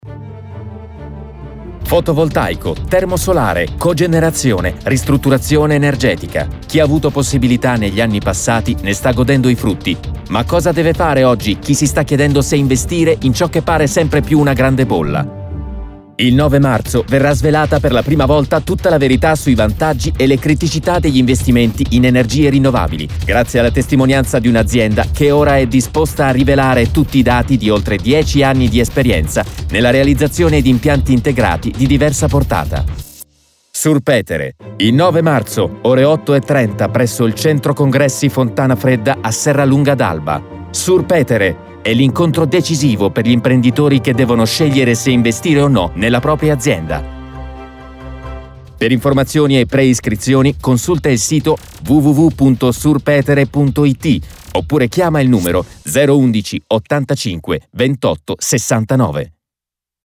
Spot Radio